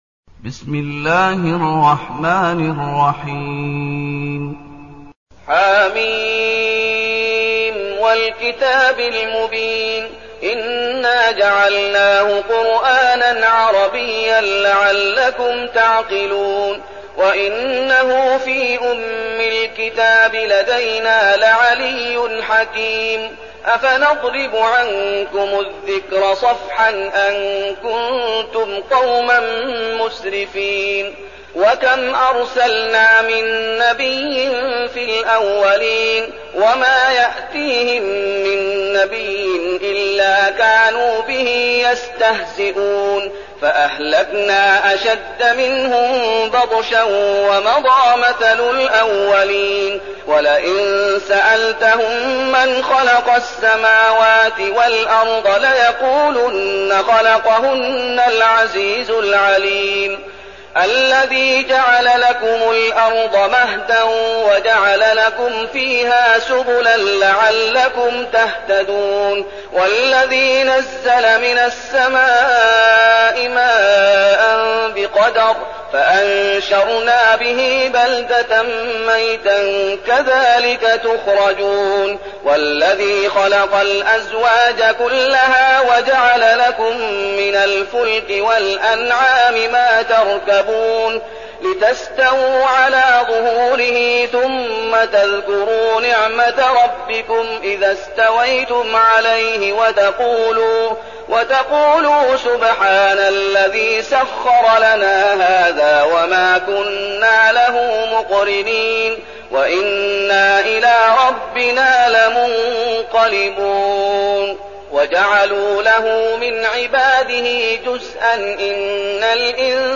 المكان: المسجد النبوي الشيخ: فضيلة الشيخ محمد أيوب فضيلة الشيخ محمد أيوب الزخرف